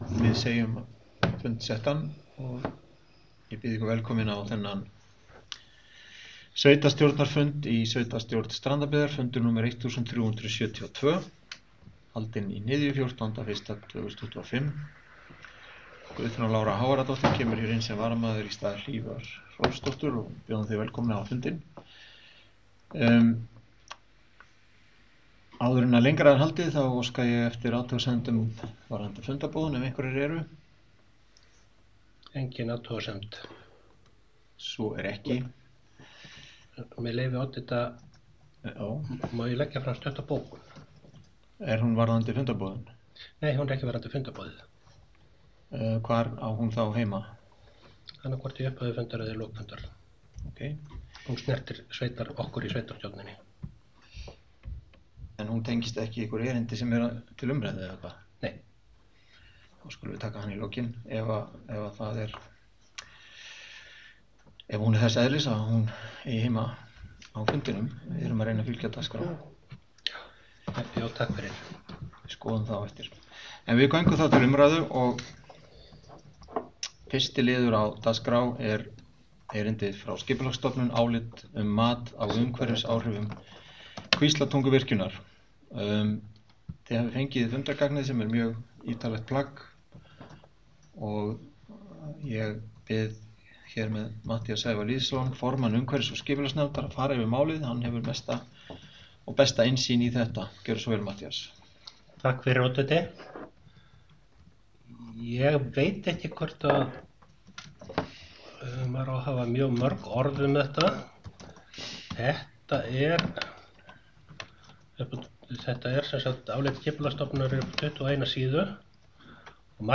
Sveitarstjórnarfundur 1372 í Strandabyggð
Fundur nr. 1372 í sveitarstjórn Strandabyggðar var haldinn þriðjudaginn 14. janúar 2025 kl. 16.00 í Hnyðju, Höfðagötu 3, Hólmavík.
Fundurinn er jafnframt tekinn upp í hljóðskrá.